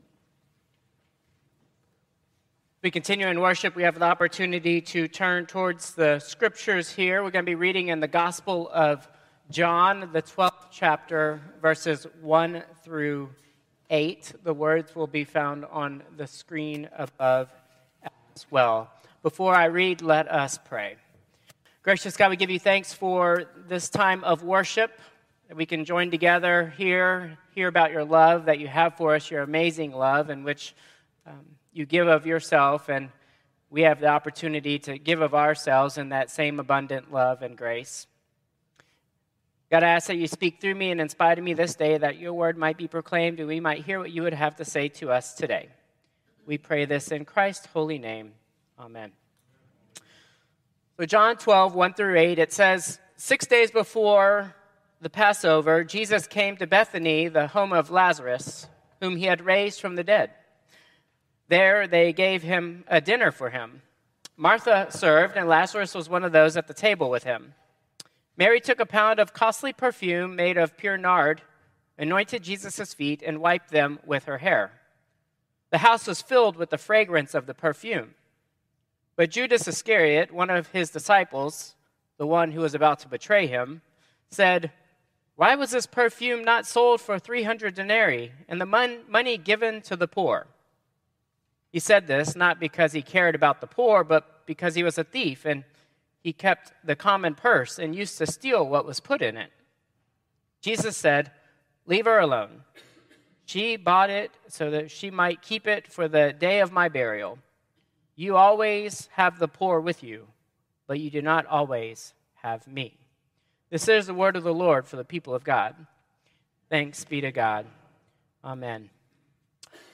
Traditional Service 4/6/2025